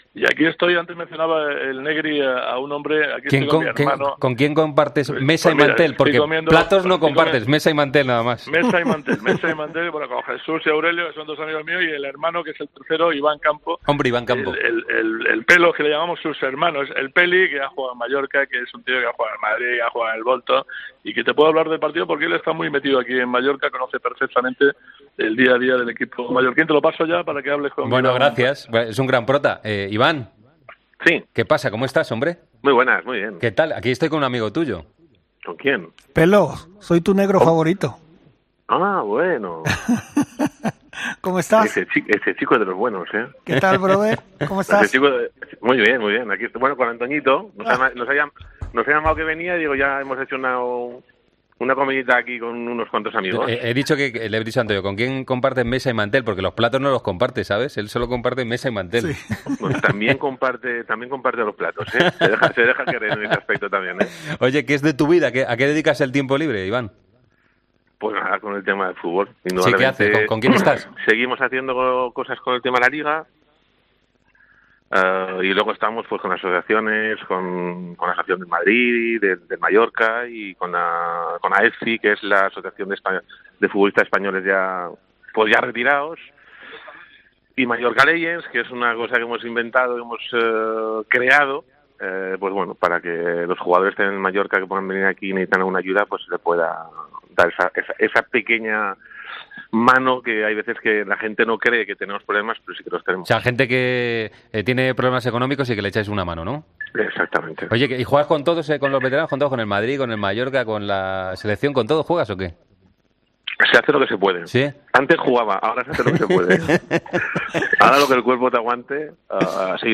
Hablamos con el exjugador del Mallorca sobre el partido entre el conjunto balear y el Atlético de Madrid.